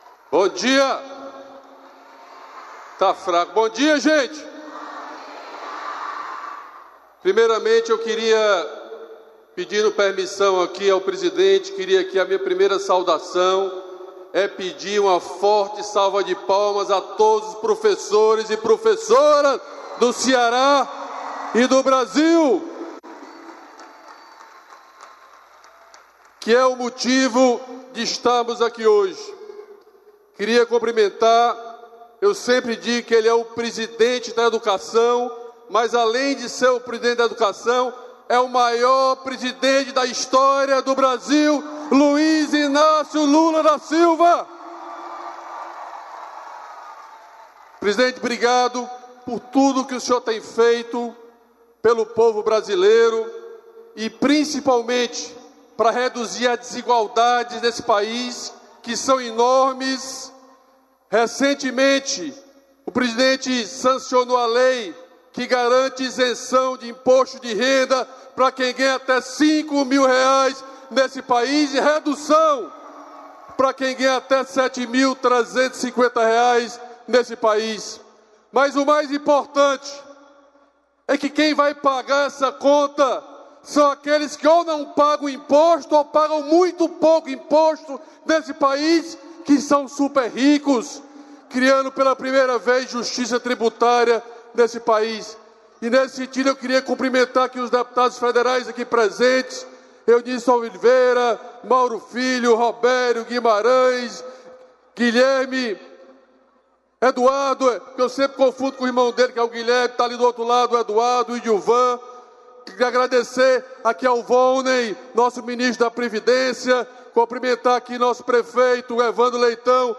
Íntegra dos discursos dos ministros Luiz Marinho, do Trabalho e Emprego; Alexandre Silveira, de Minas e Energia, e Geraldo Alckmin, do Desenvolvimento, Indústria, Comércio e Serviços e vice-presidente da República, na cerimônia de anúncio do Programa de Renovação da Frota Naval do Sistema Petrobras, em Angra dos Reis (RJ), nesta segunda-feira (17).